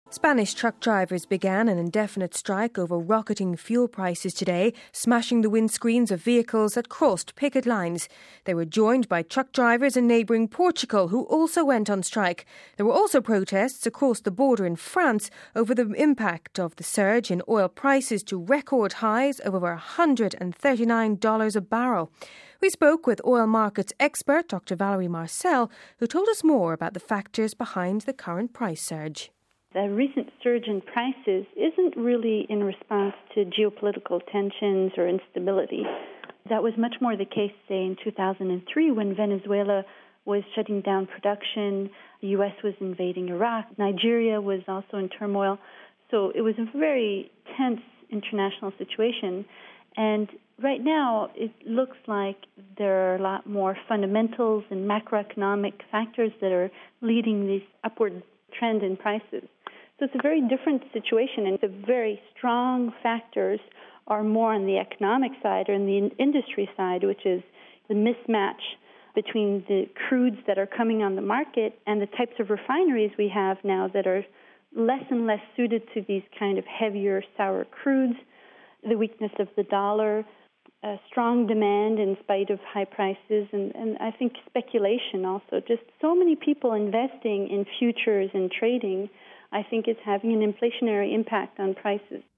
We spoke with Oil Markets expert